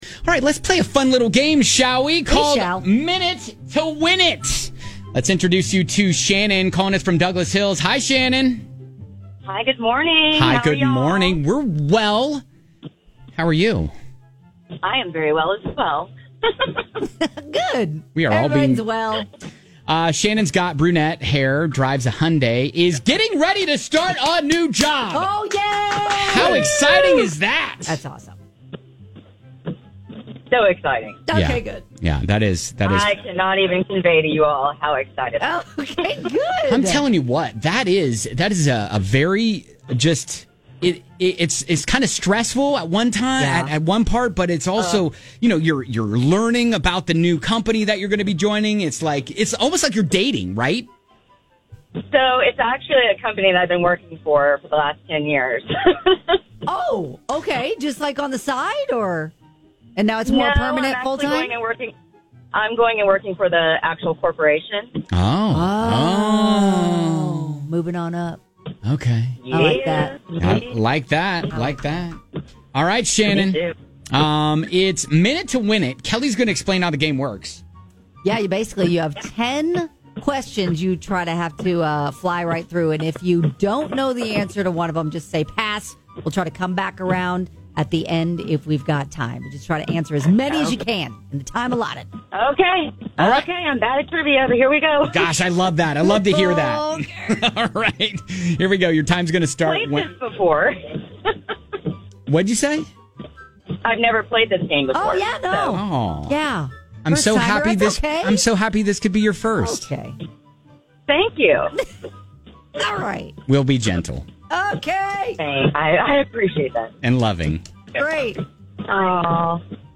You’ll have :60 to try and answer 10 pop-culture questions. Get as many right as you can before the buzzer goes off and win tickets to AJR or Iliza tickets!